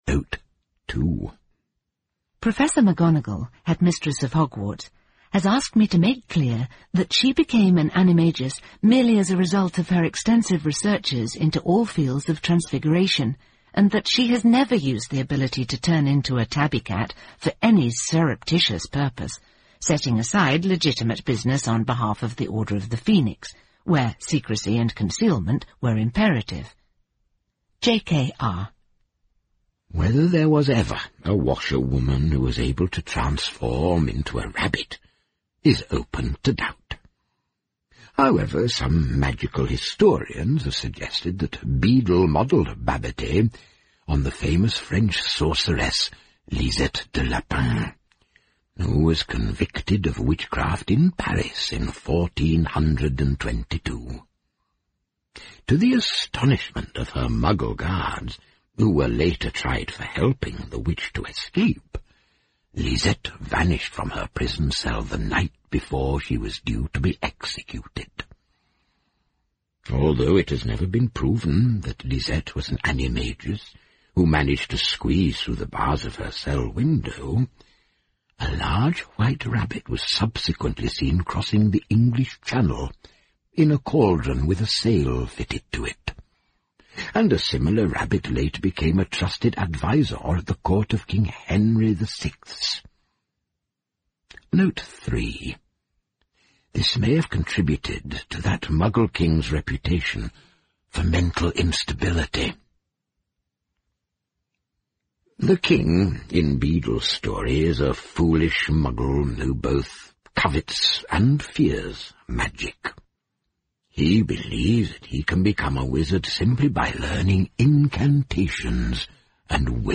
在线英语听力室诗翁彼豆故事集 第22期:兔子巴比蒂和她的呱呱树桩(6)的听力文件下载,《诗翁彼豆故事集》栏目是著名的英语有声读物，其作者J.K罗琳，因《哈利·波特》而闻名世界。